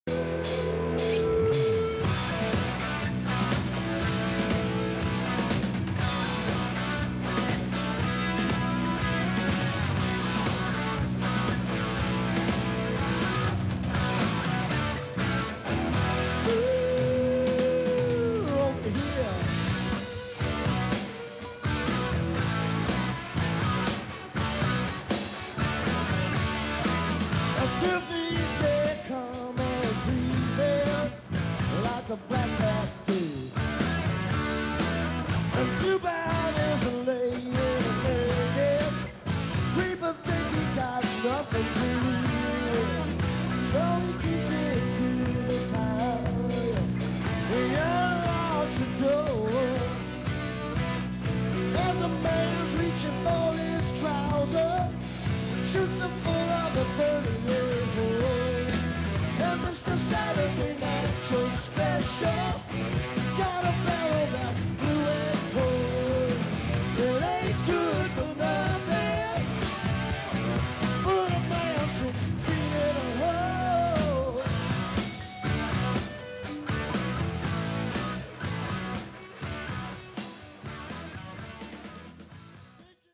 on air performance